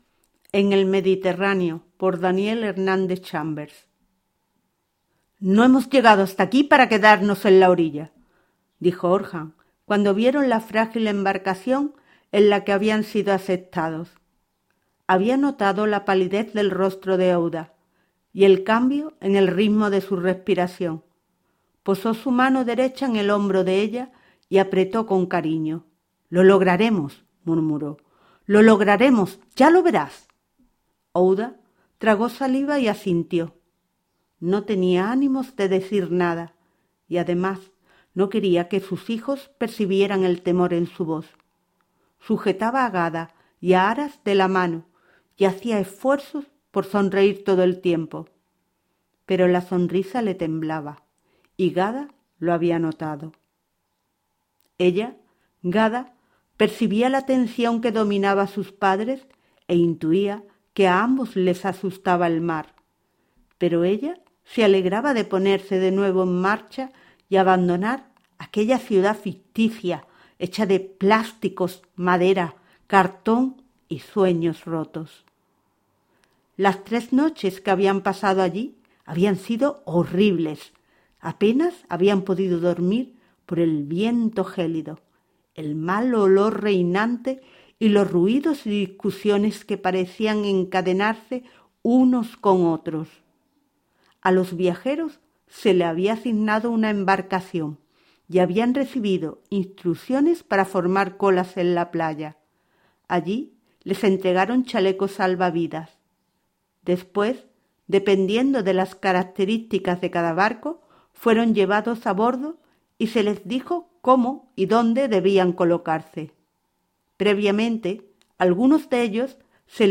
LECTURA | Y TÚ, ¿CÓMO SERÍAS?